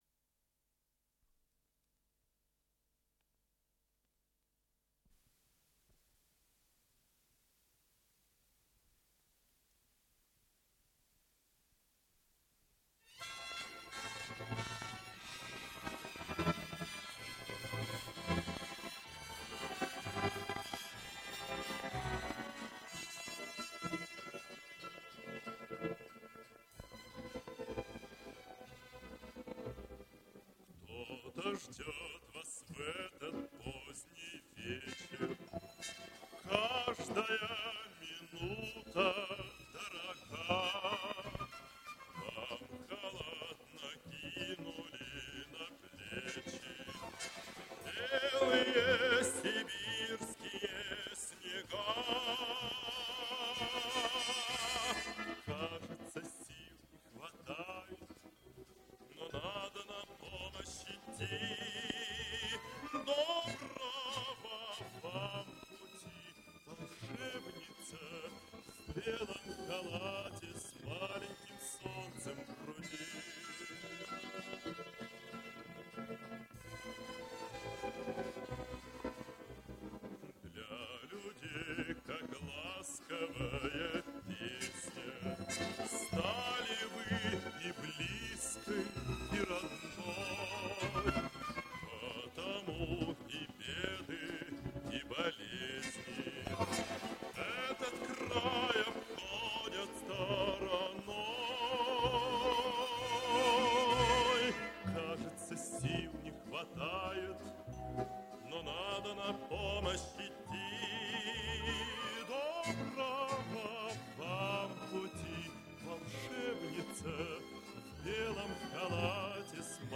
запись 1964 года в  псевдостереозвучании.
Ей уже 54 года. И второй канал идёт с выпадениями.
С магнитной ленты.